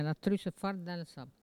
Elle provient de Saint-Hilaire-de-Riez.
Catégorie Locution ( parler, expression, langue,... )